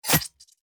attack_hit_4.mp3